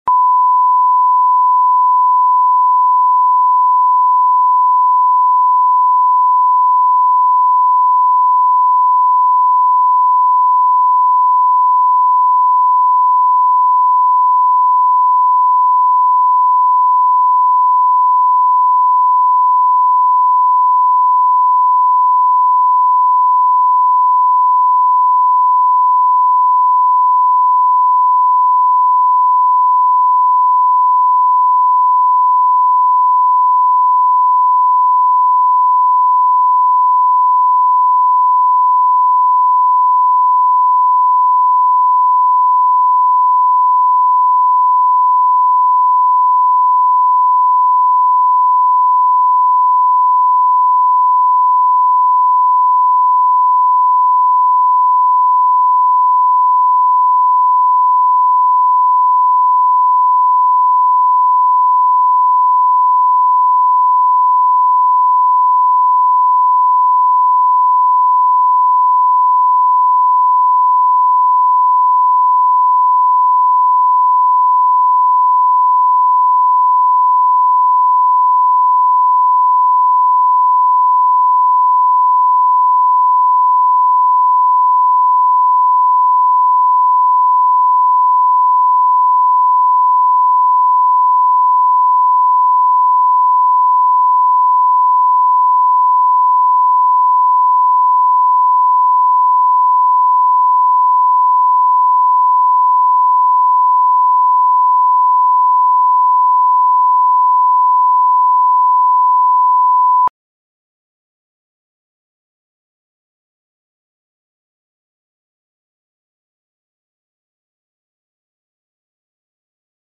Аудиокнига Круиз